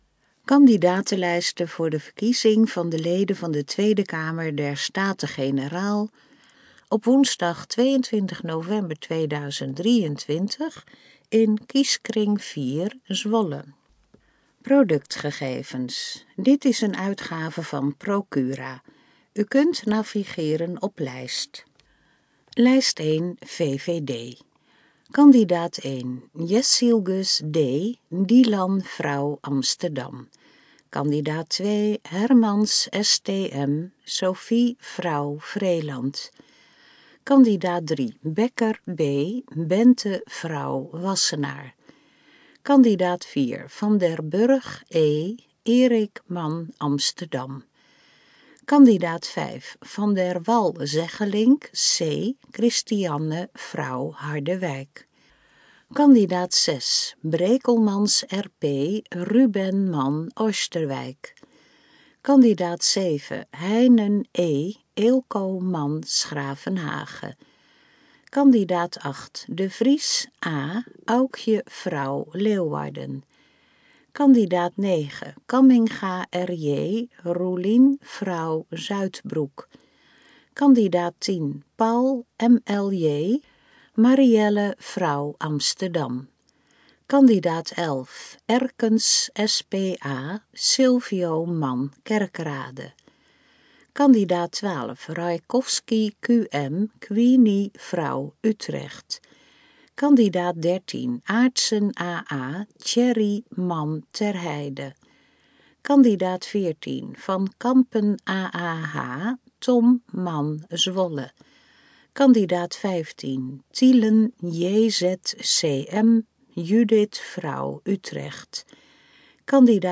Kandidatenlijst in grootletter Kandidatenlijst in gesproken vorm
HAH-in-gesproken-vorm_TK23_Kieskring-4-Zwolle.mp3